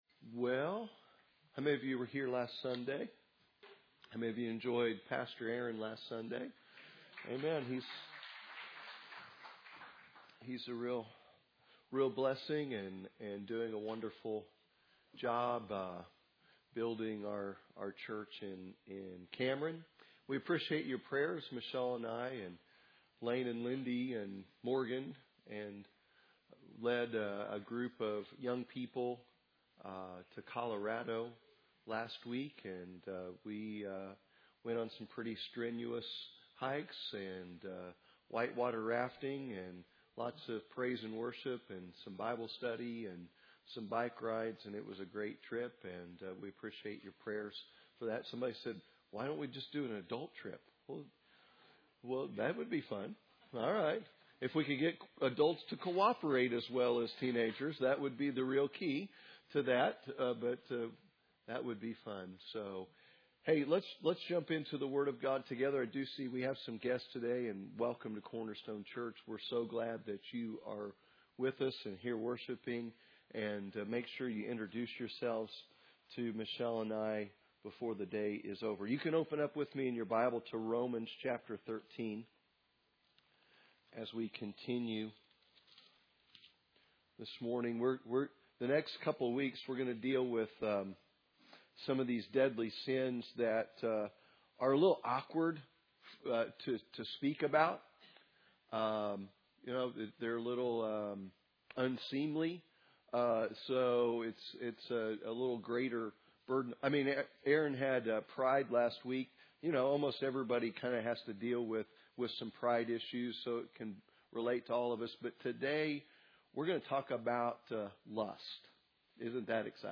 Sunday Morning Service The Seven Deadly Sins